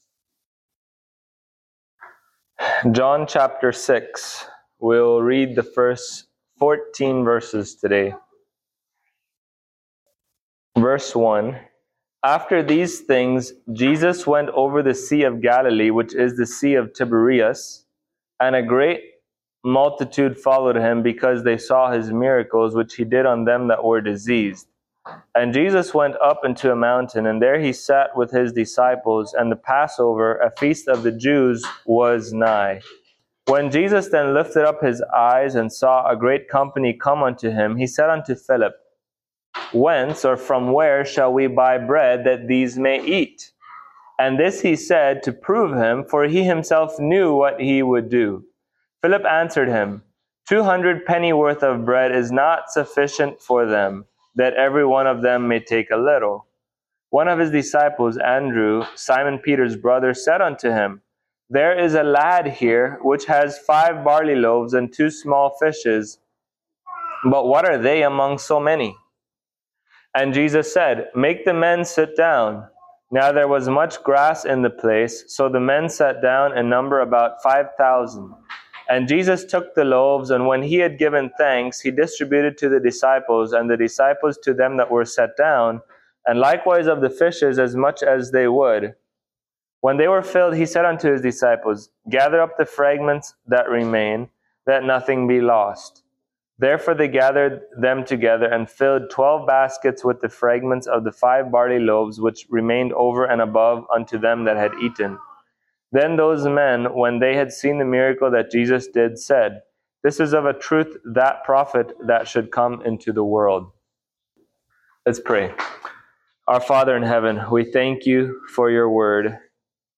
John Passage: John 6:1-14 Service Type: Sunday Morning Topics